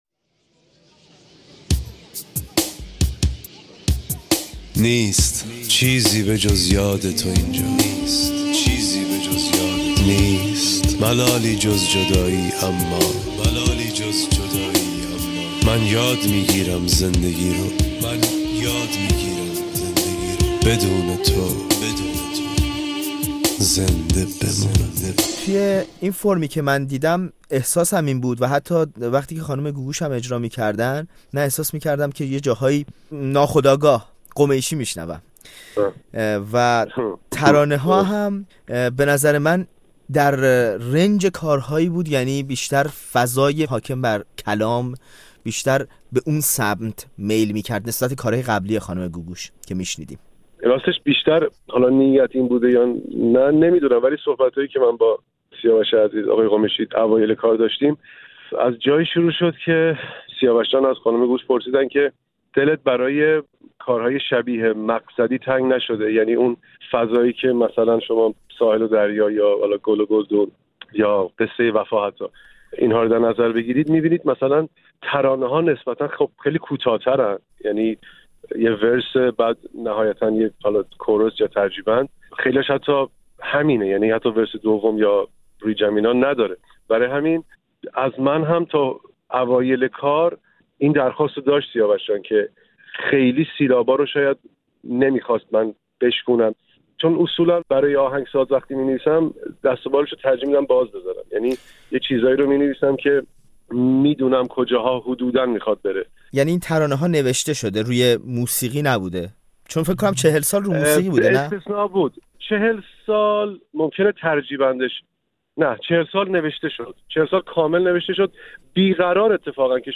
سهراب‌کُشان ترانه؛ گپ‌وگفت خودمانی با رها اعتمادی
گفت‌وگوی دو ترانه‌باز که از قضا در حرفه رسانه نزدیکند و یکی رها اعتمادی ترانه‌نویس است.